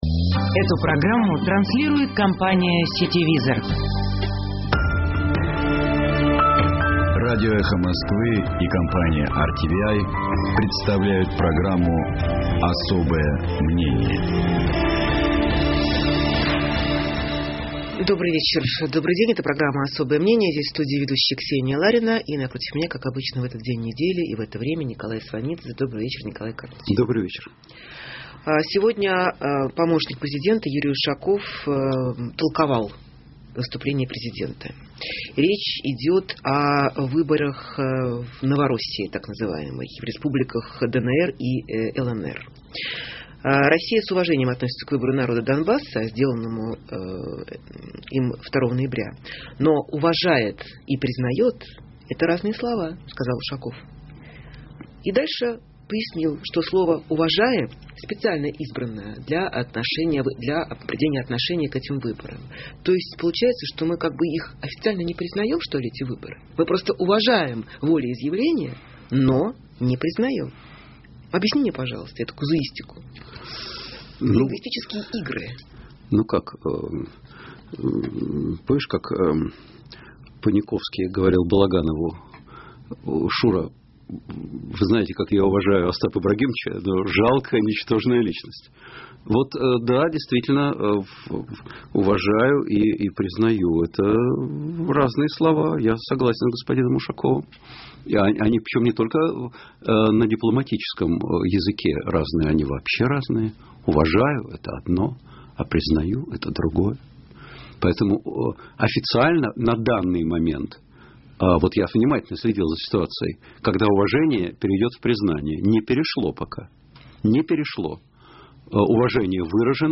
Здесь, в студии ведущая Ксения Ларина, и напротив меня, как обычно, в этот день недели и в это время Николай Сванидзе.